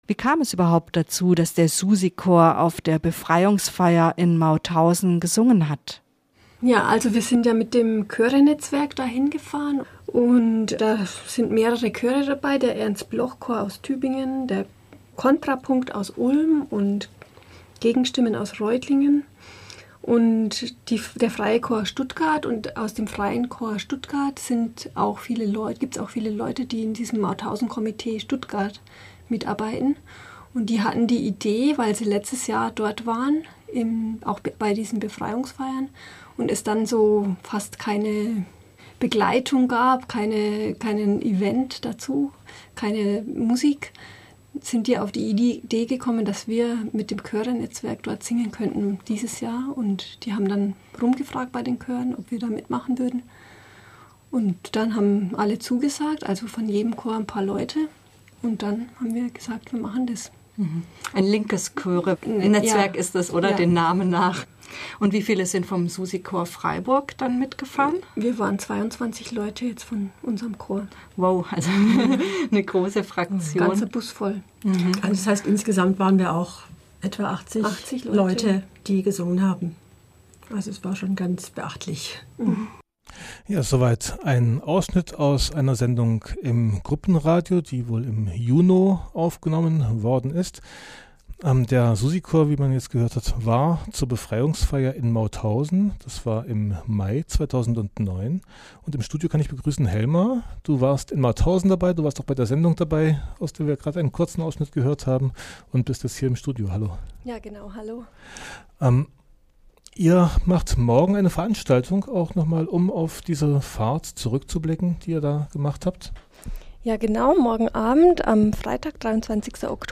war zu Gast in Punkt12